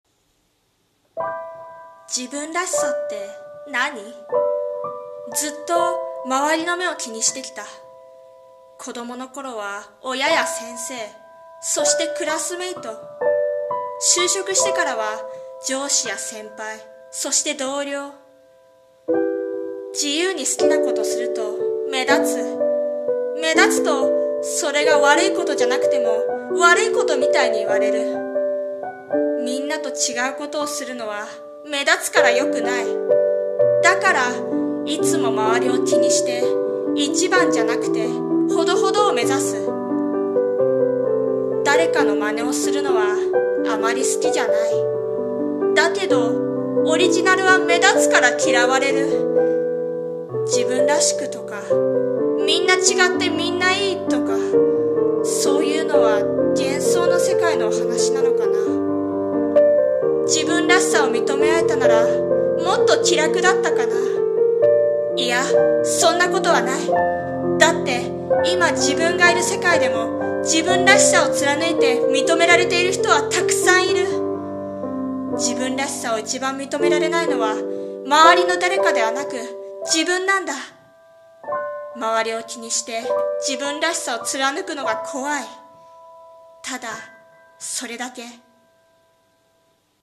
さんの投稿した曲一覧 を表示 【声劇台本】自分らしさ